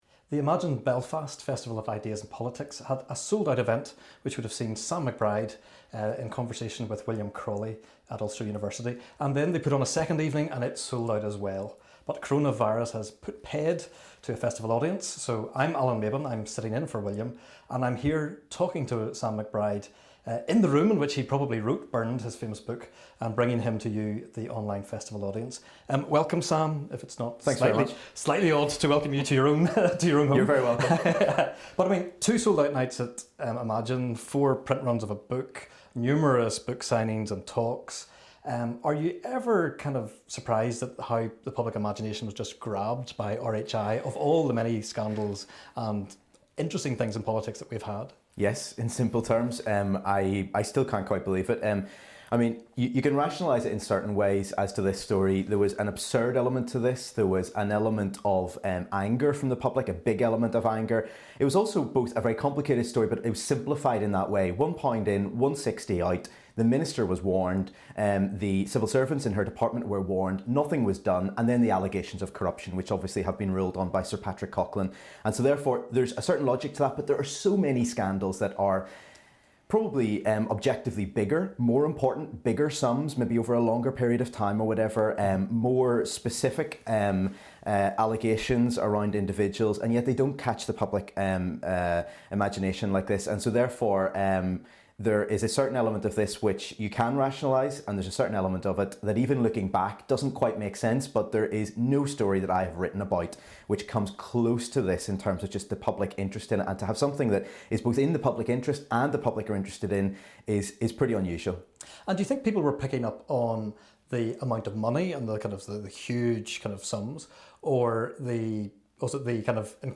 in conversation
as part of Imagine! Belfast Festival of Ideas and Politics.